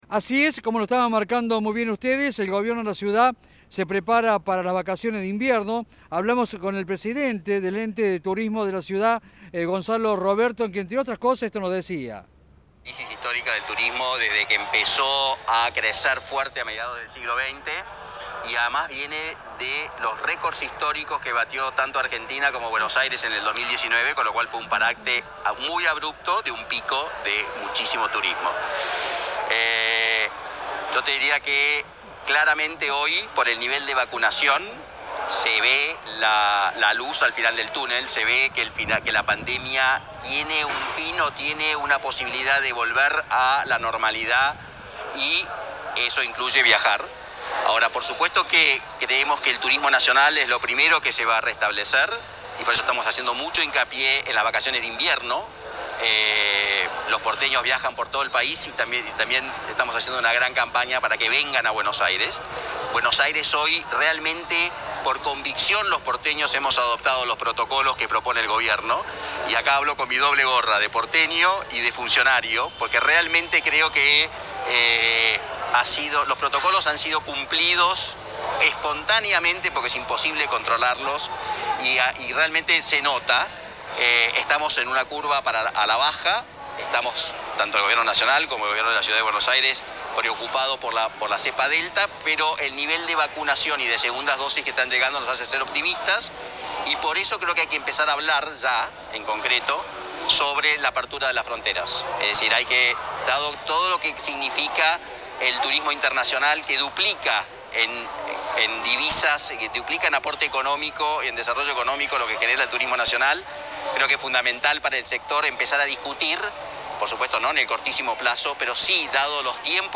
"Estamos en una crisis histórica del turismo", dijo a Cadena 3 el presidente del Ente de Turismo de la Ciudad de Buenos Aires Gonzalo Robredo.